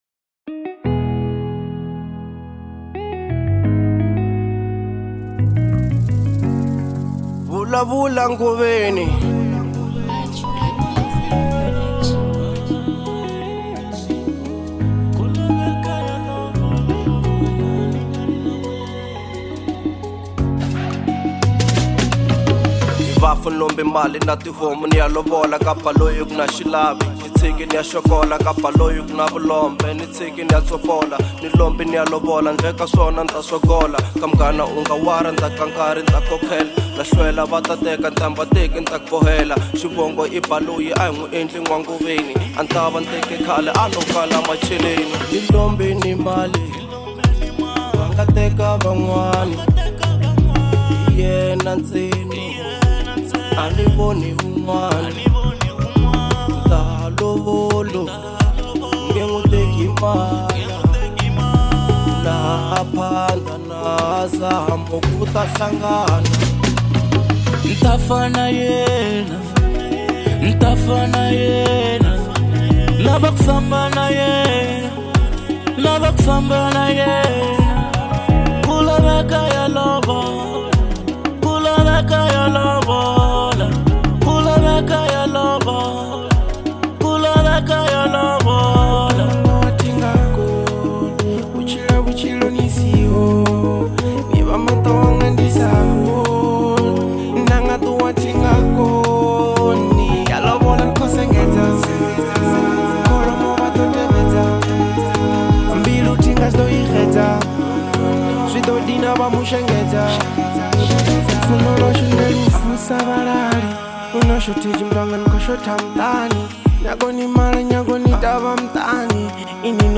02:45 Genre : Afro Pop Size